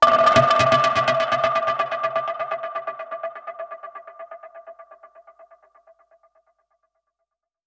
Index of /musicradar/dub-percussion-samples/125bpm
DPFX_PercHit_B_125-05.wav